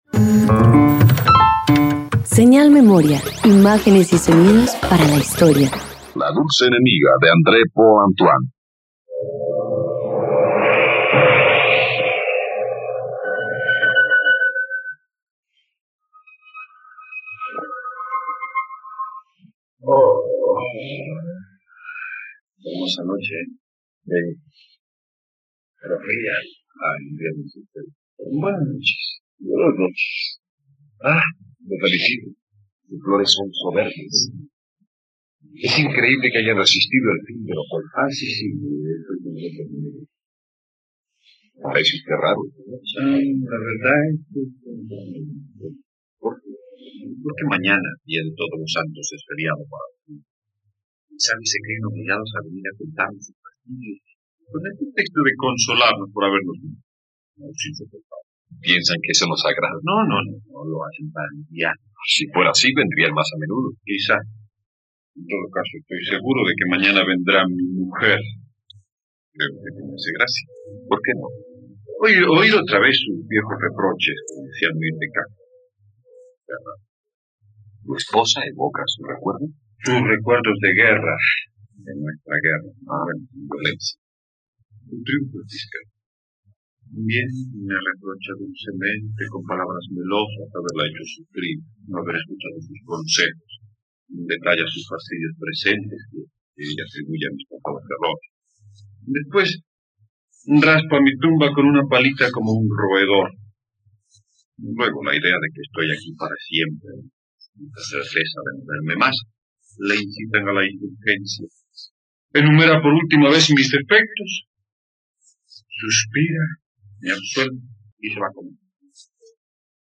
La dulce enemiga - Radioteatro dominical | RTVCPlay
..Conoce la adaptación de “La dulce enemiga" para radio.